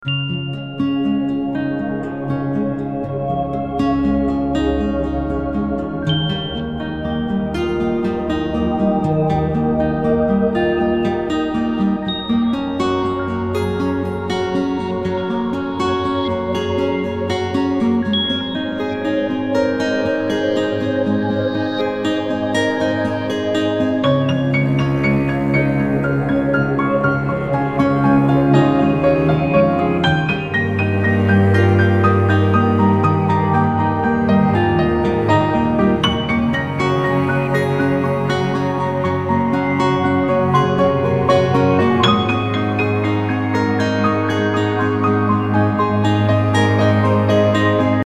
平静的旋律，短暂的等待。